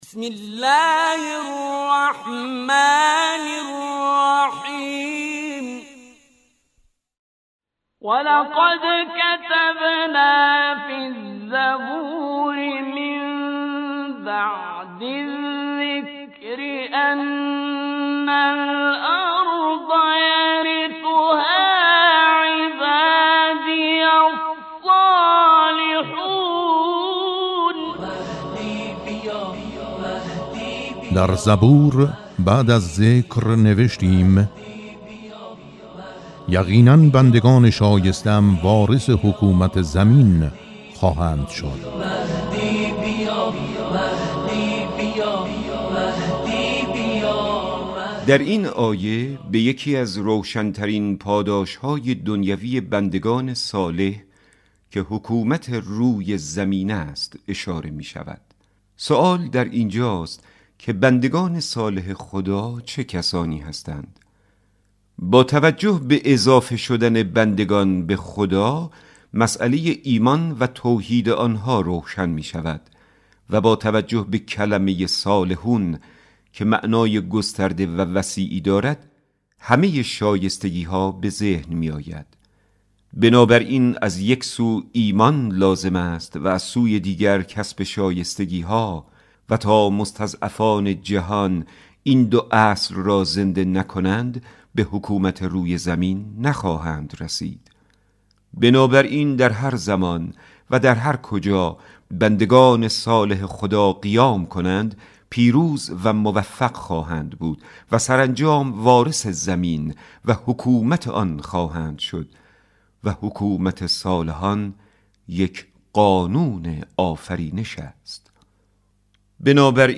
به مناسبت سالروز ولادت حضرت ولی‌عصر (عج) برخی از این آیات را در مجموعه «آیه‌های مهدوی» با صدای کریم منصوری، قاری بنام کشور همراه با تفسیر کوتاه این آیه می‌شنوید. در اولین قسمت آیه 105 سوره مبارکه انبیاء ارائه می‌شود.